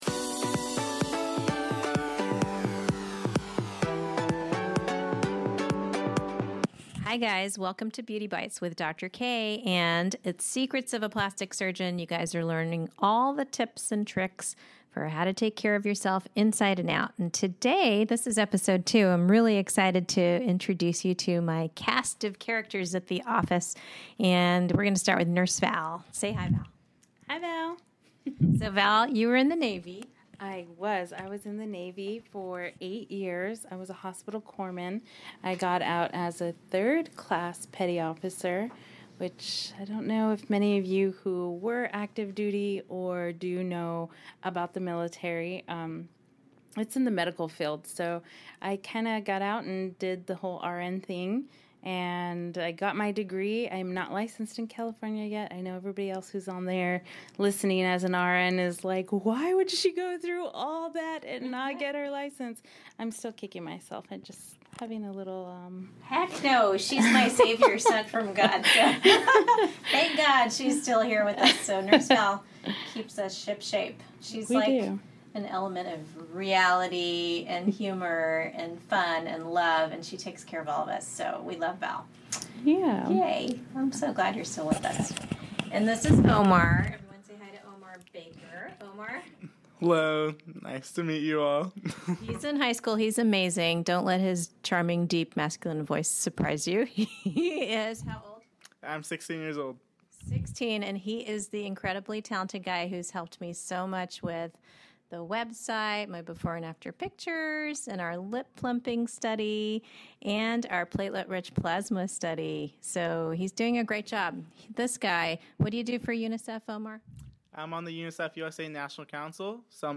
My office squad joins me to discuss tips for preserving the beauty of your cheeks, getting your perfect pouty lips, and maintaining the youthful grace of a slender neck.